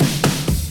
02_15_drumbreak.wav